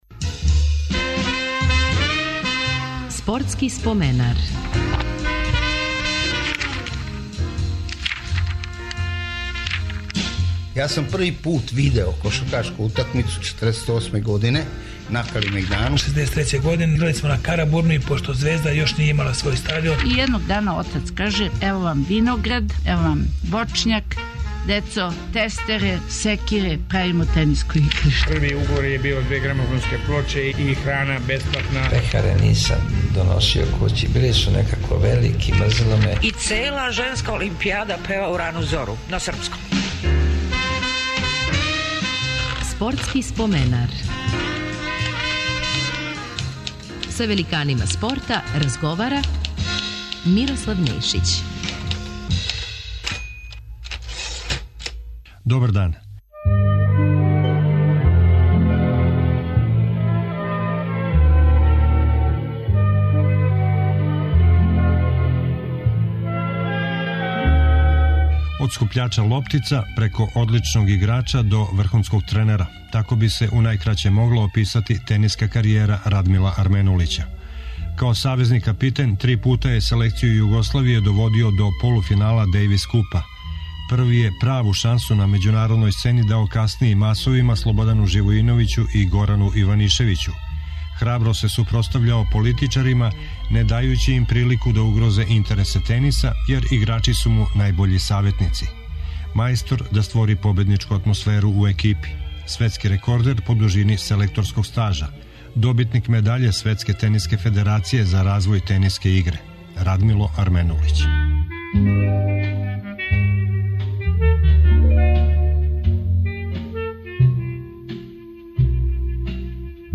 Ове недеље репризирамо разговор са тенисером